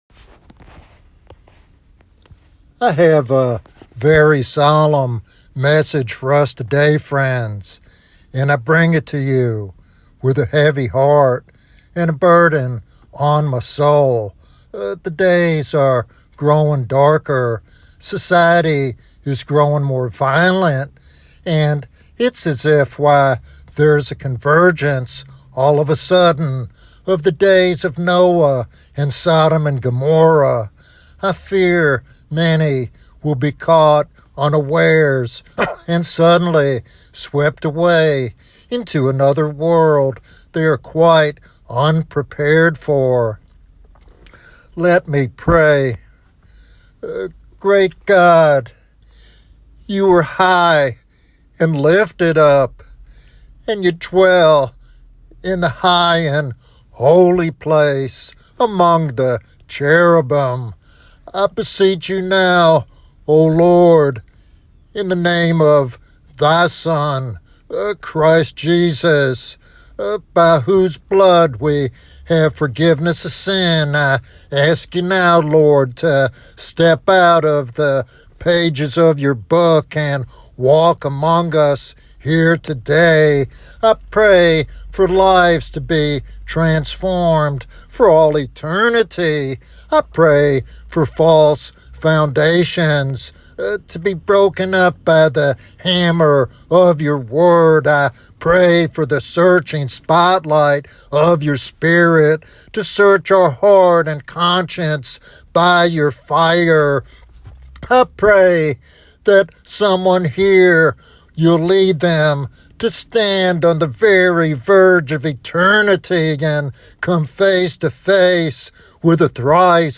In this solemn and urgent sermon